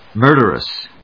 音節mur・der・ous 発音記号・読み方
/mˈɚːdərəs(米国英語), mˈəːdərəs(英国英語)/